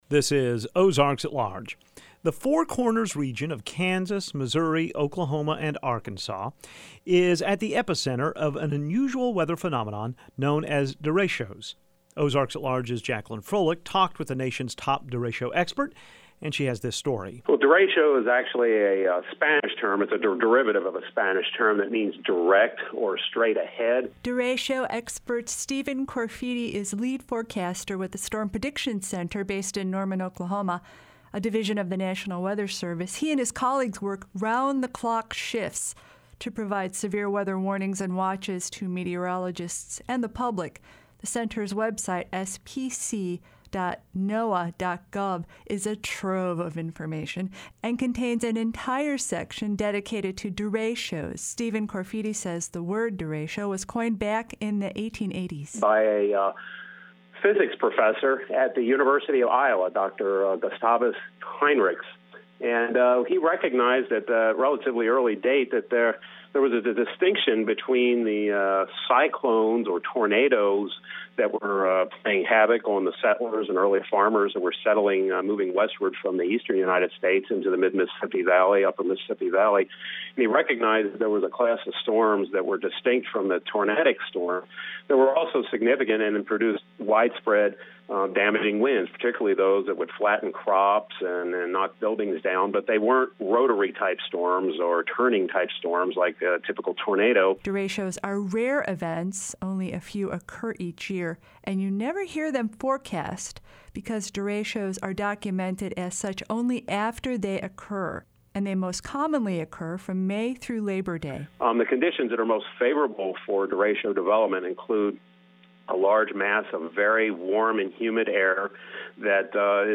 The four corners region of Kansas, Missouri, Oklahoma and Arkansas are at the epicenter of an unusual weather phenomenon categorized as “derechos.” An expert at the National Weather Service Storm Prediction Center explains.